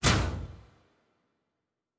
pgs/Assets/Audio/Fantasy Interface Sounds/Special Click 28.wav at master
Special Click 28.wav